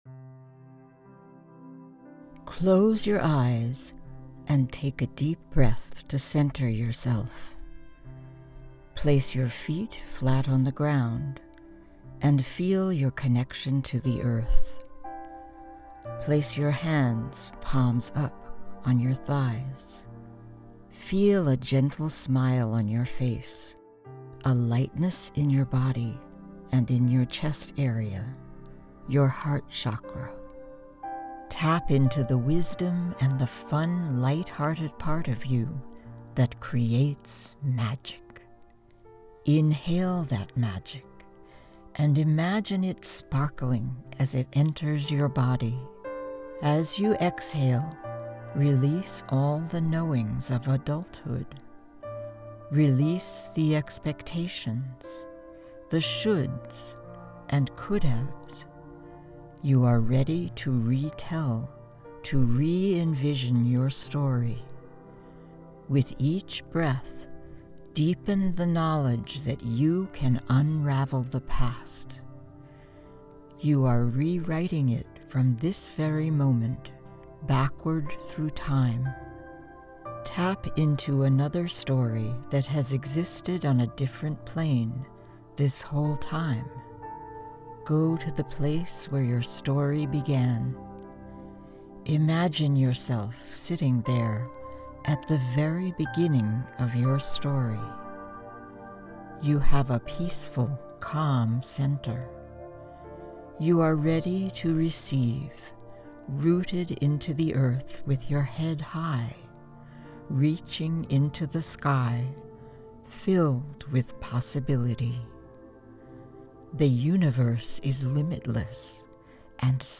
Female
English (North American)
Adult (30-50)
My voice is warm, rich, and trustworthy. I'm classy, conversational, and authoritative, and I'm great at bringing a sense of awe and wonder with a sense of intimacy.
Guided Meditation Excerpt
Words that describe my voice are warm, authoritative, trustworthy.